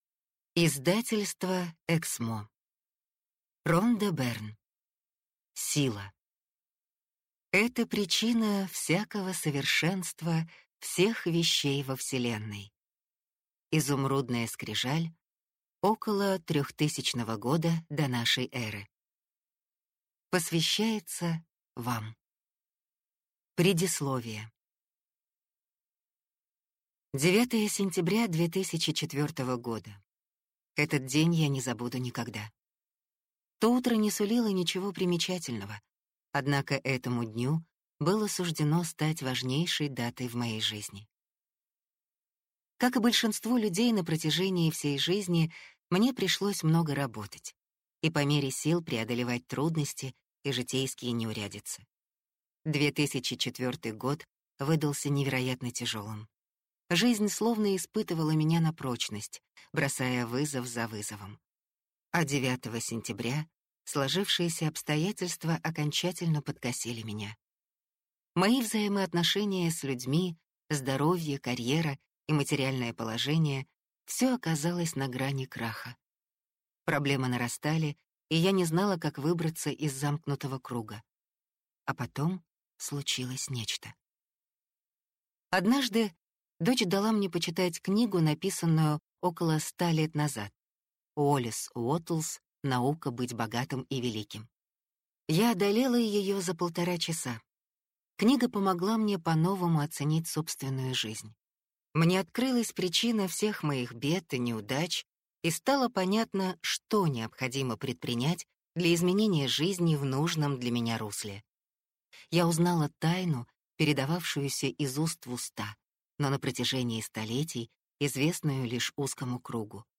Аудиокнига Сила | Библиотека аудиокниг